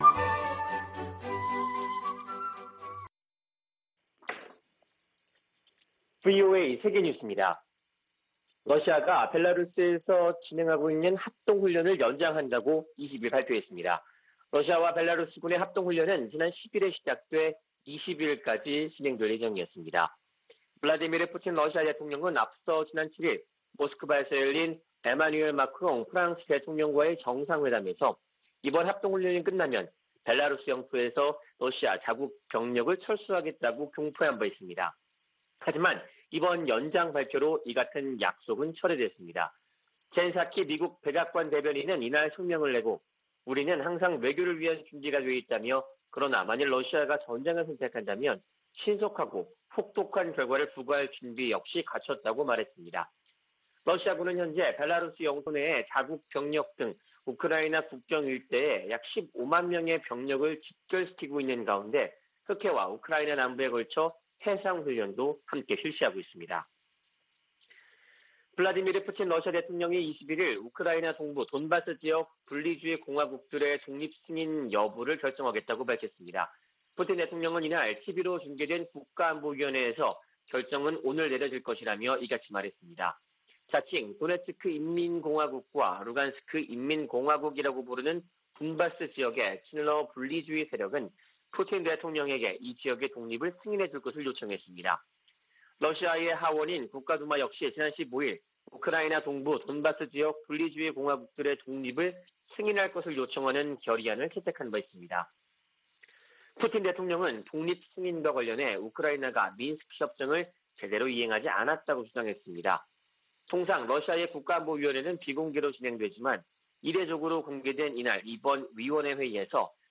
VOA 한국어 아침 뉴스 프로그램 '워싱턴 뉴스 광장' 2021년 2월 22일 방송입니다. 존 볼튼 전 백악관 국가안보보좌관은 북한 정권 교체 가능성과 핵 프로그램에 대한 무력 사용이 배제돼선 안 된다고 주장했습니다. 북한 비핵화 문제는 미-북 간 최고위급 논의가 필요하다고 마이크 폼페오 전 미 국무장관이 말했습니다. 한국의 북한 출신 국회의원들이 유엔 북한인권특별보고관에게 북한 반인도 범죄자들에 고소·고발 지원을 요청했습니다.